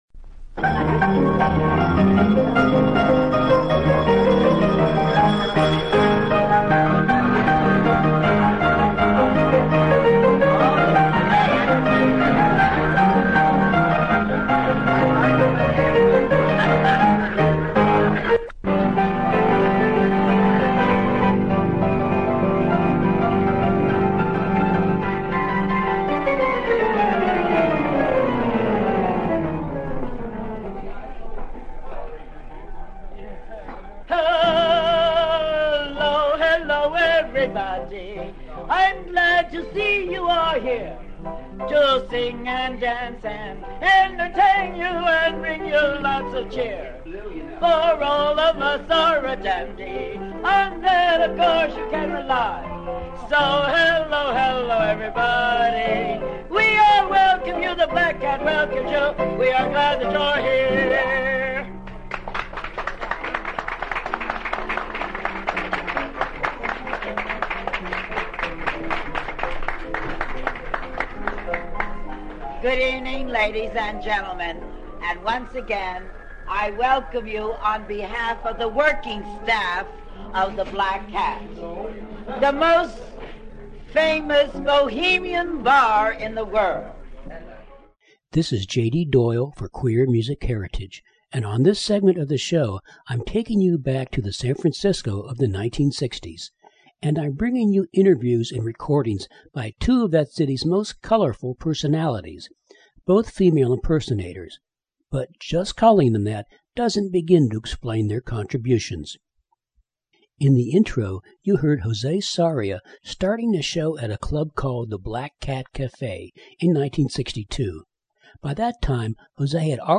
November 2012 Three special interviews packed into two hours!